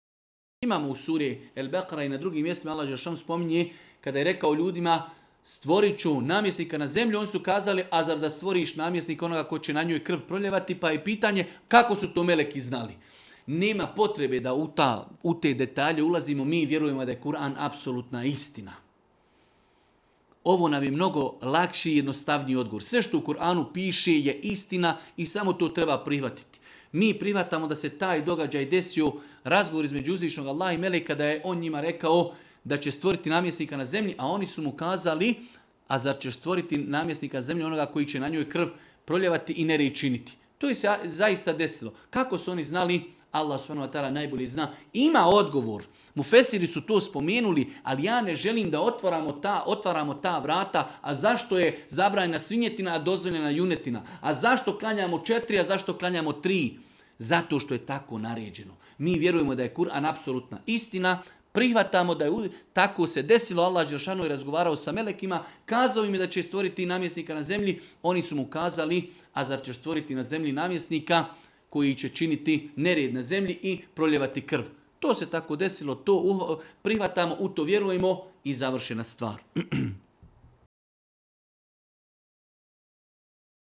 video predavanju